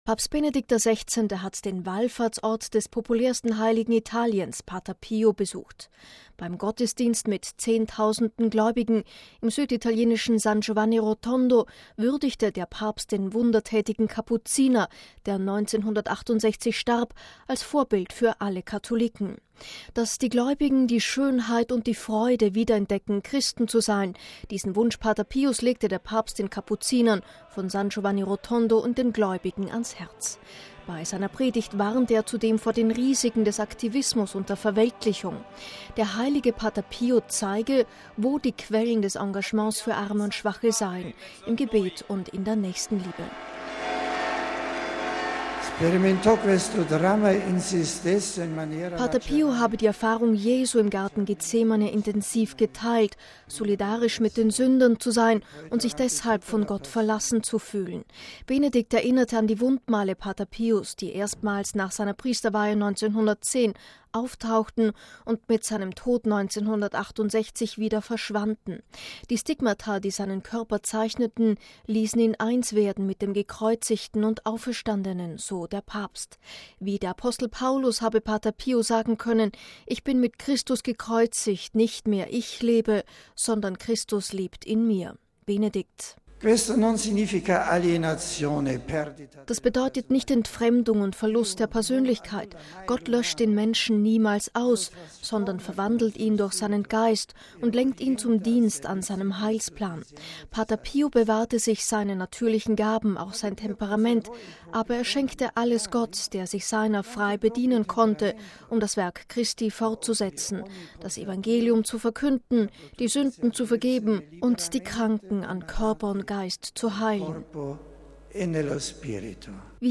Bei seiner Predigt am Wallfahrtsort Pater Pios warnte der Papst zudem vor den „Risiken des Aktivismus und der Verweltlichung“.